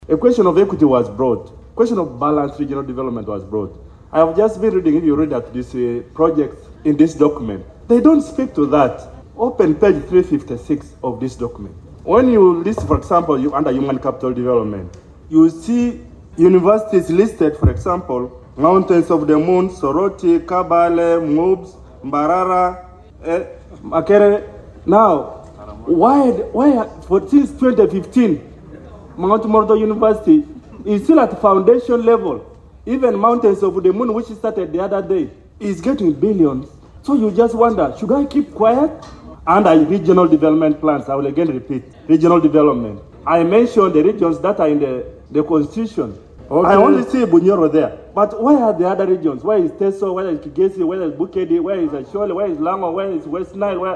The Chairperson of the Budget Committee, Hon. Patrick Isiagi chaired the meeting that is reviewingthe draft NDP IV.
Pian County MP, Hon. Remigio Achia  similarly pointed out that several parts of the country have been consistently excluded from national development plans.